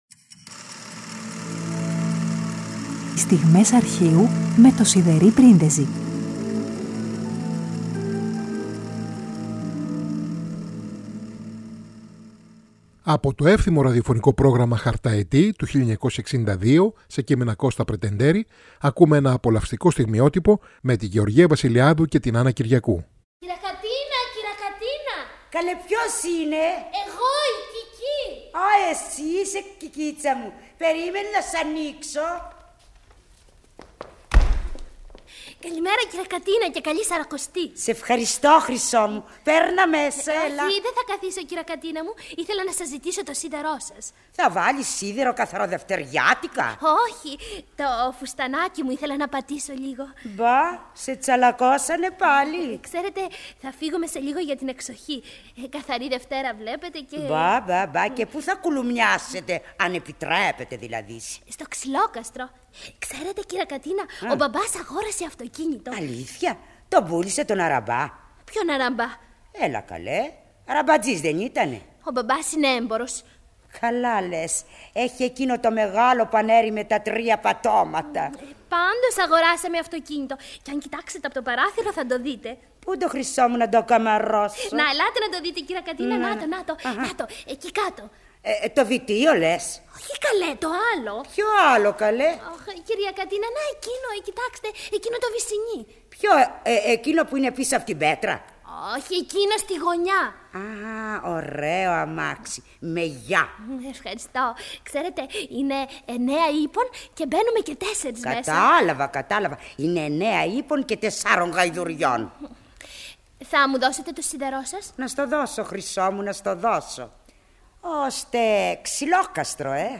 Καθαρή Δευτέρα 07 Μαρτίου: Ακούμε ένα απολαυστικό σκετς με τη Γεωργία Βασιλειάδου και την Άννα Κυριακού από το εύθυμο ραδιοφωνικό πρόγραμμα «Χαρταετοί» του 1962, σε κείμενα Κώστα Πρετεντέρη.